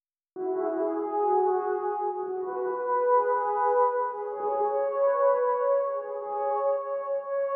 violons_studio_strings_96.wav